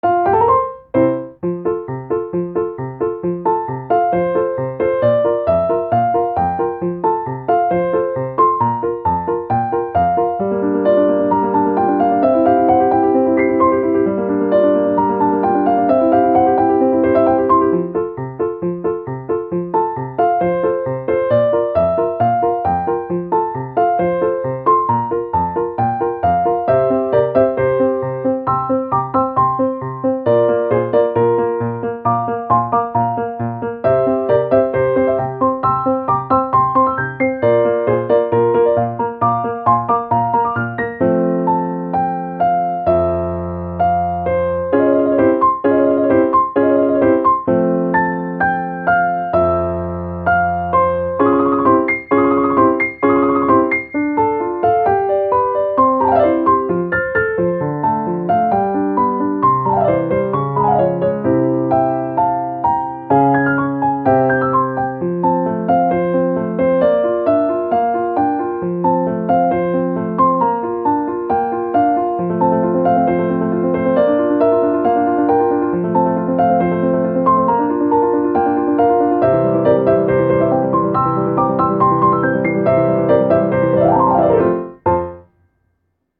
ogg(R) - 明るい 軽やか 楽しい
トランポリンのようにポンポン展開が変わっていく楽しい曲。